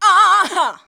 AHA 3.wav